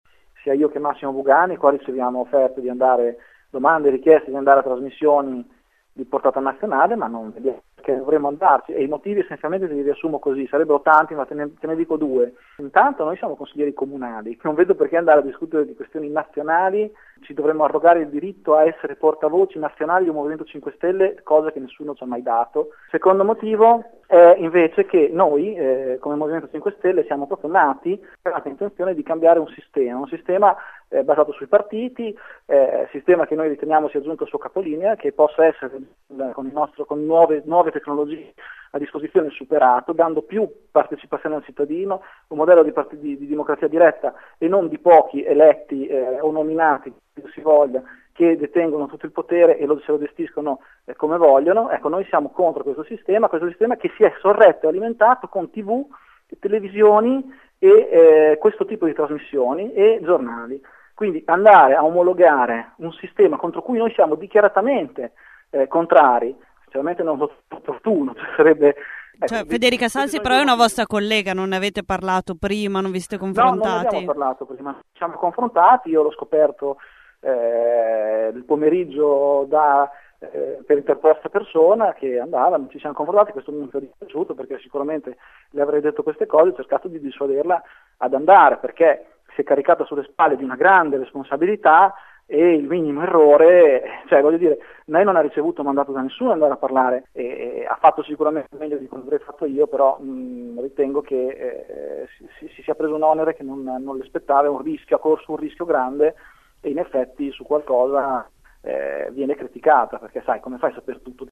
Della stessa idea anche il consigliere Marco Piazza che ai nostri microfoni ribadisce i motivi per cui si è sempre rifiutato di presenziare ai talk show e dice “Federica si è caricata di una responsabilità non banale, che non le spettava“.